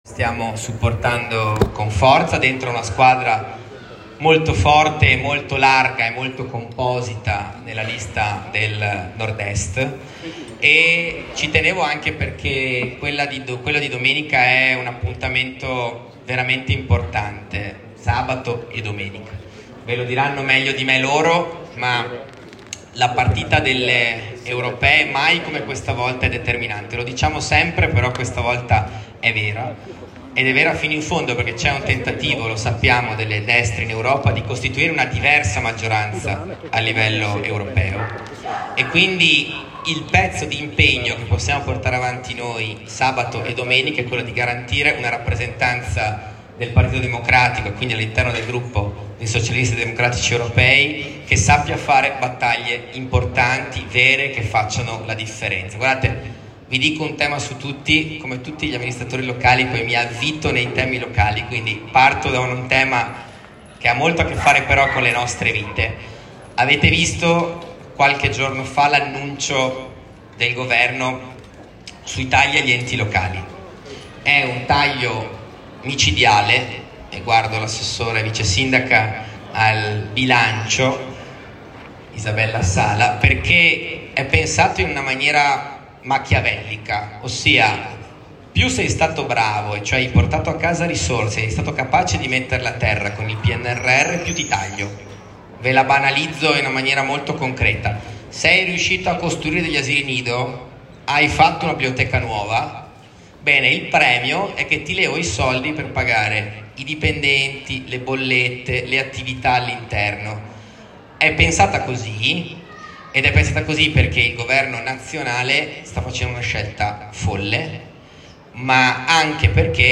La campagna elettorale per le elezioni europee di sabato 8 giugno e domenica 9 si è conclusa con un evento significativo in Piazzetta Palladio a Vicenza.